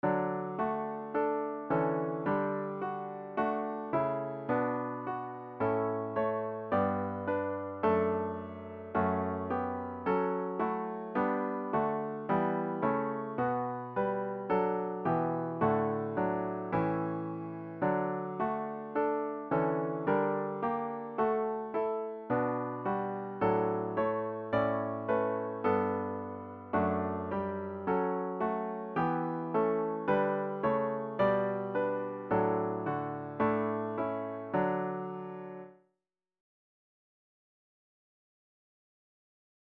The hymn should be performed at an expectant♩= ca. 108.